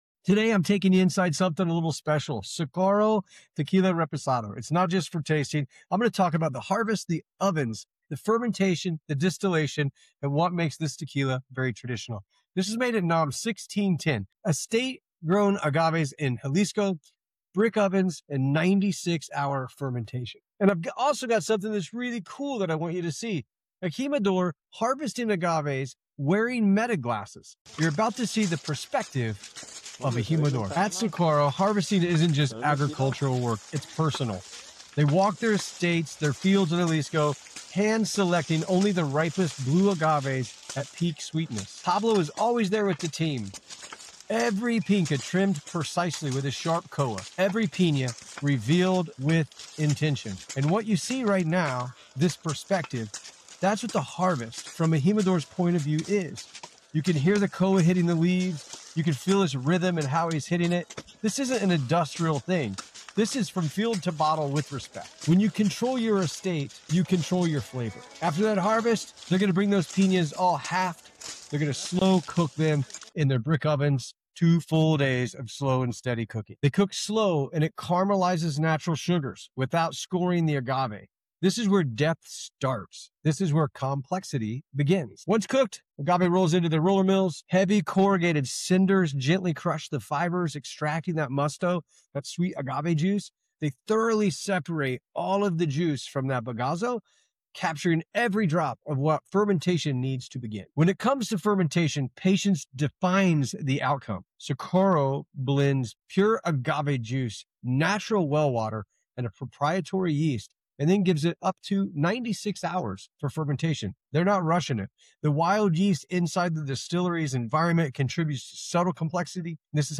Is This a REAL Estate Grown Reposado? | Socorro Tequila Review